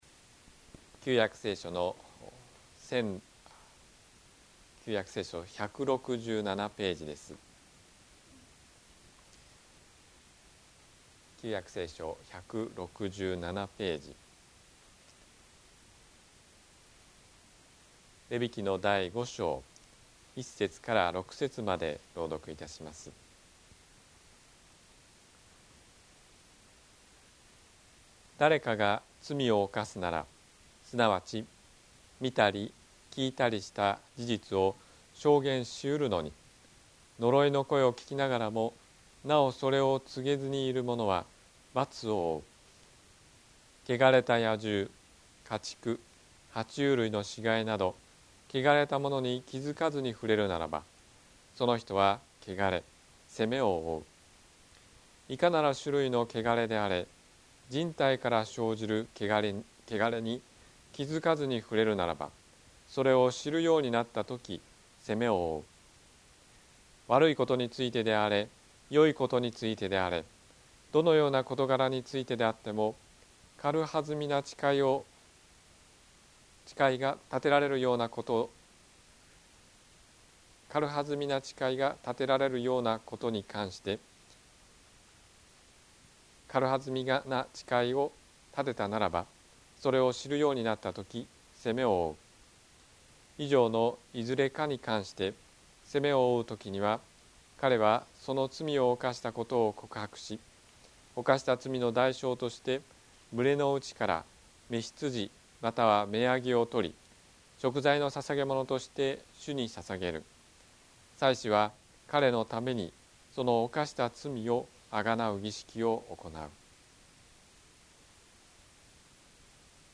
説教アーカイブ 日曜 夕方の礼拝